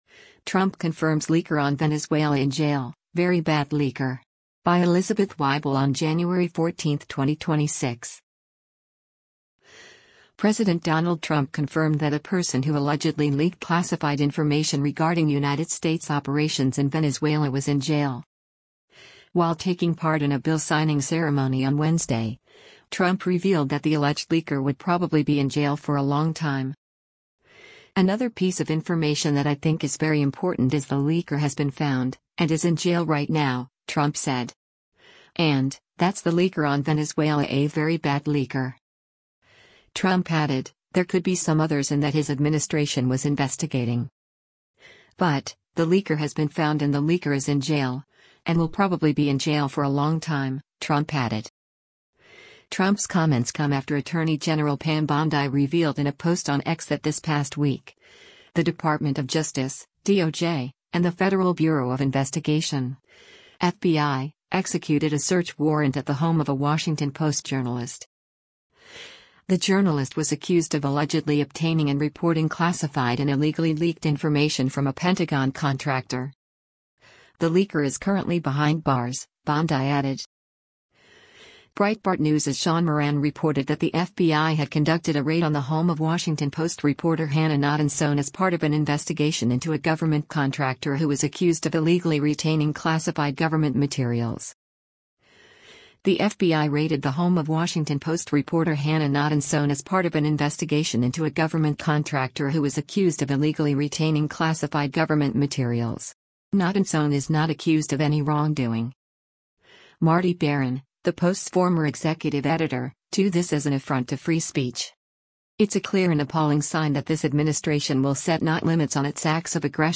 While taking part in a bill signing ceremony on Wednesday, Trump revealed that the alleged leaker would “probably be in jail for a long time.”